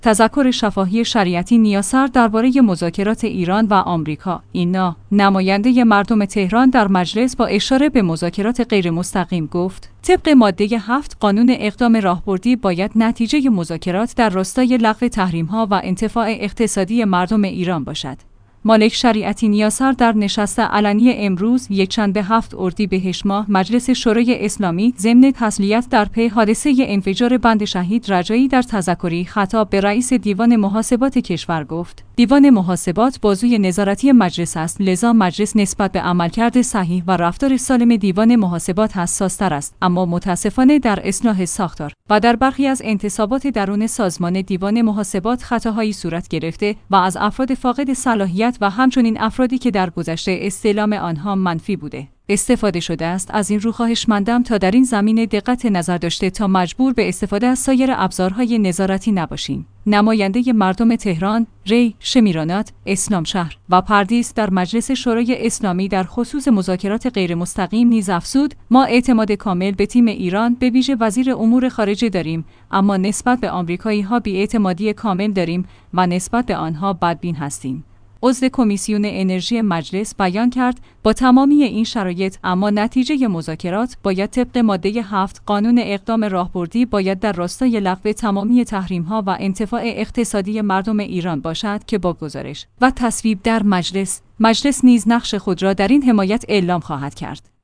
تذکر شفاهی شریعتی نیاسر درباره مذاکرات ایران و آمریکا